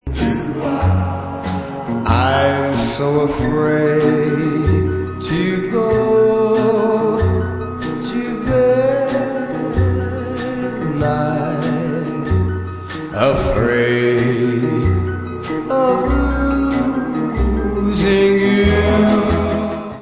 50's rock